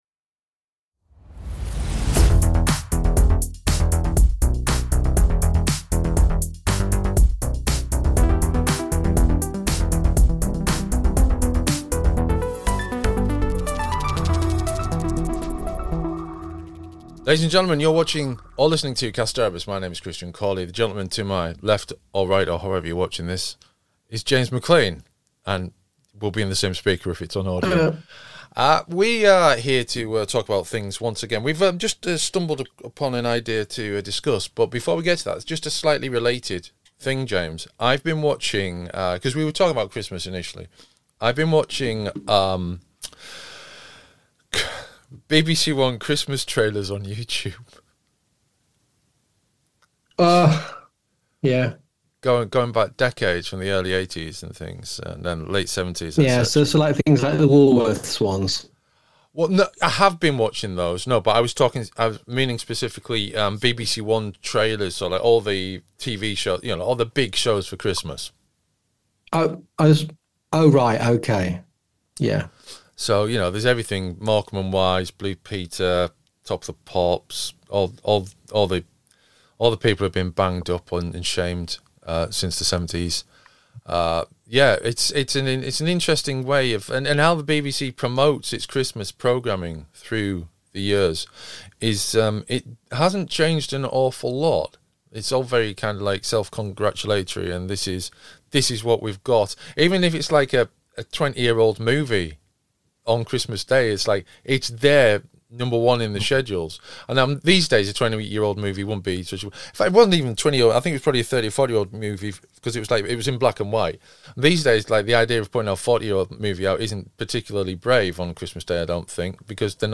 a new chat